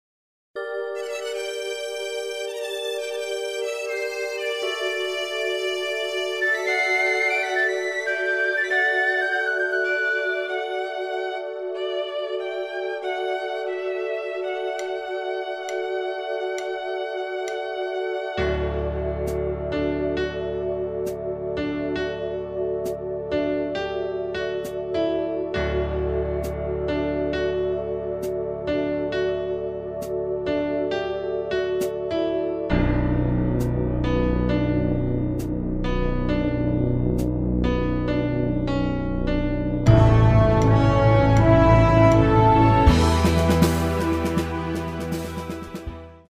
live Bercy 2001